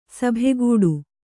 ♪ sabhe gūḍu